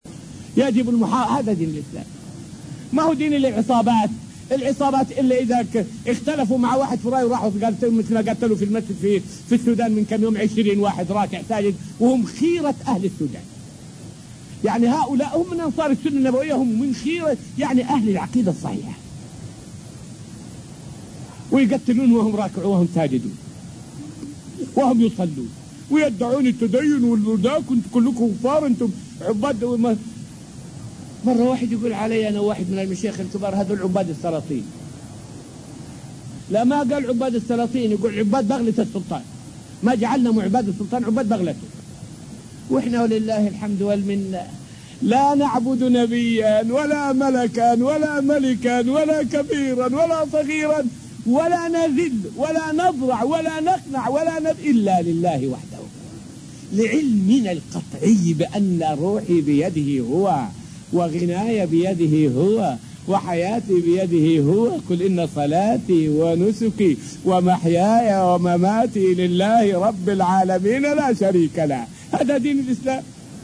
فائدة من الدرس الثالث والعشرون من دروس تفسير سورة البقرة والتي ألقيت في المسجد النبوي الشريف حول صور من التعدي على العلماء.